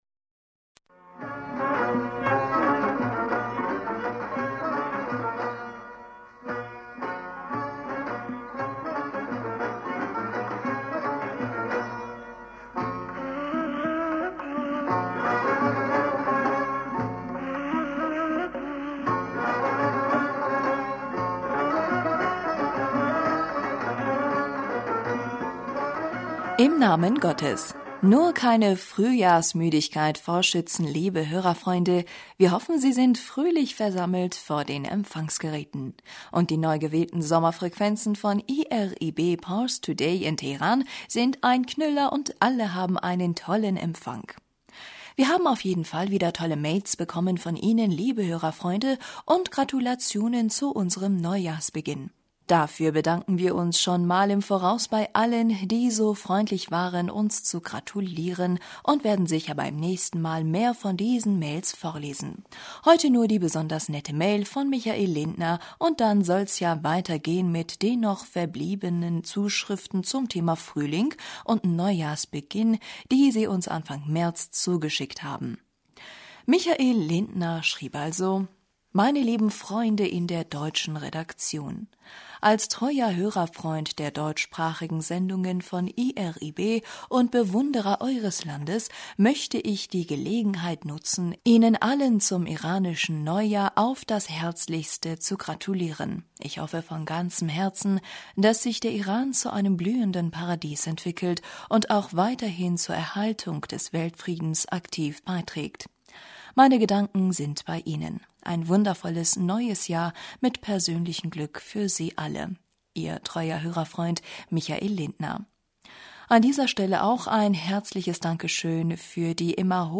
Hörerpostsendung